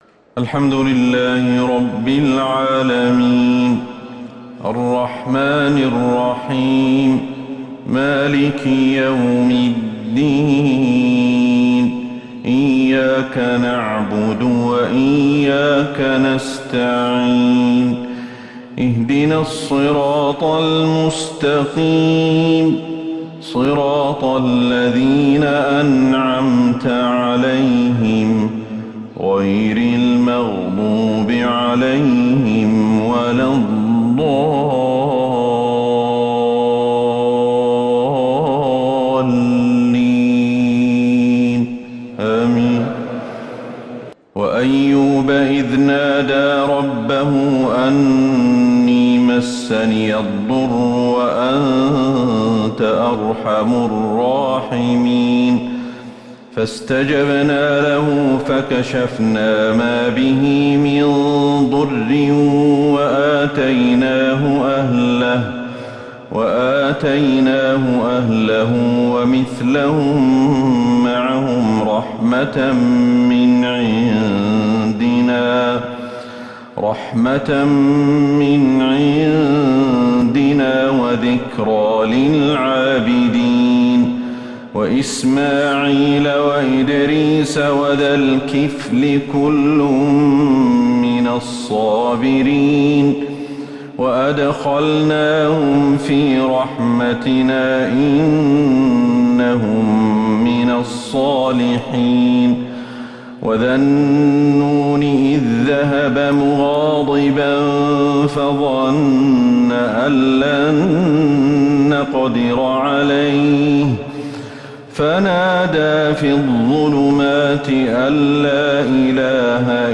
فجر الأحد 17 جمادى الأولى 1444هـ آواخر سورة {الأنبياء} > 1444هـ > الفروض - تلاوات الشيخ أحمد الحذيفي